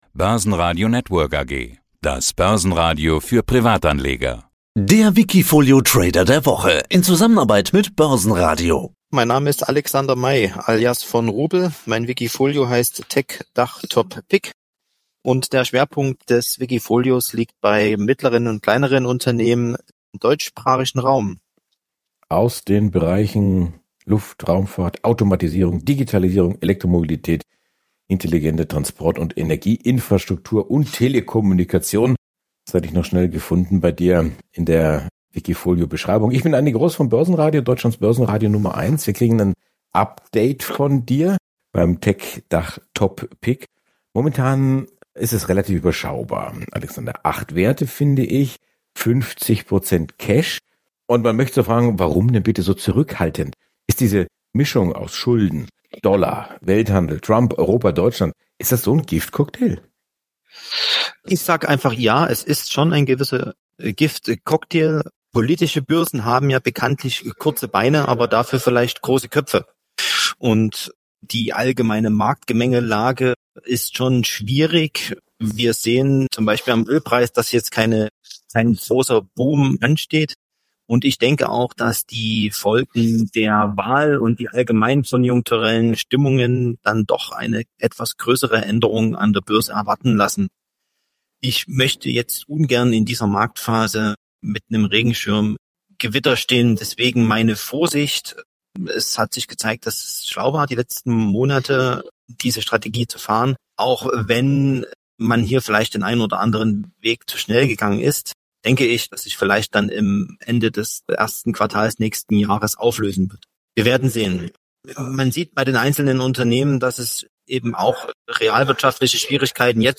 Hoher Cashanteil - wiki TecDachTopPic ~ Die besten wikifolio-Trader im Börsenradio Interview Podcast